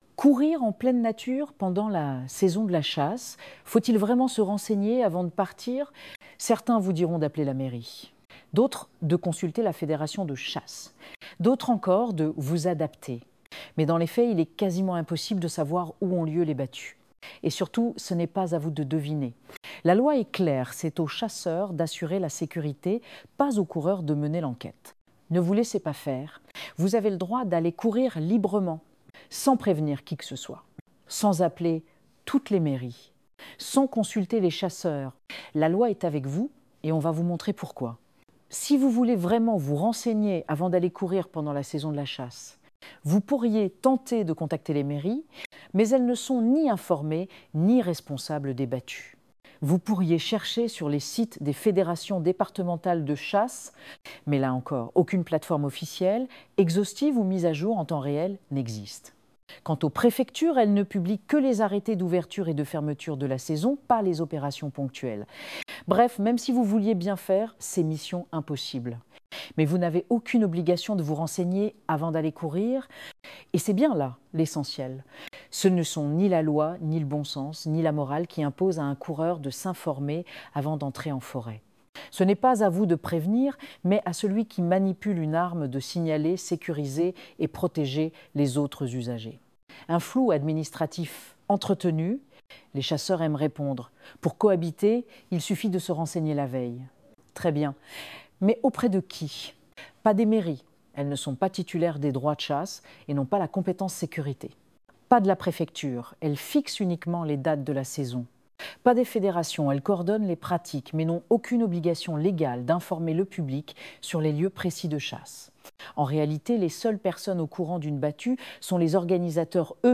Ecouter cet article sur la chasse